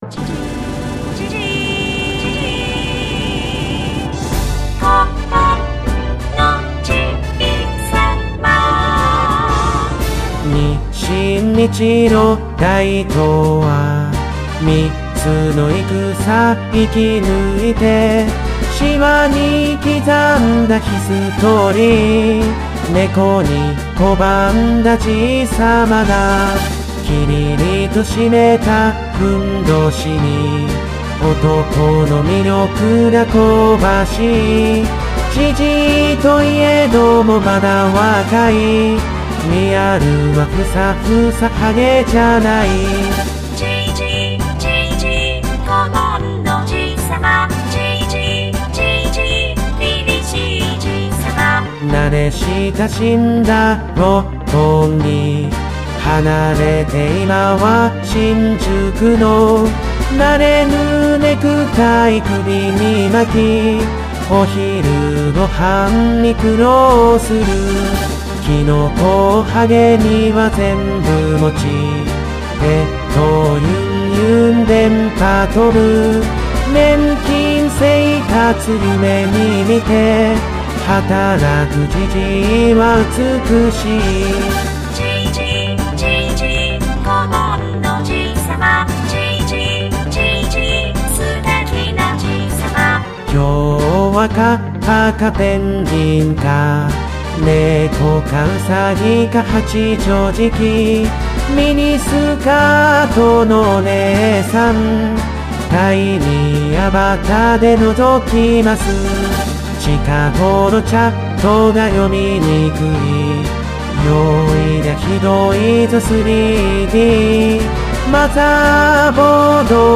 サポセンジャー お花見コンサート。
ギター I
ベース
キーボード
ドラムス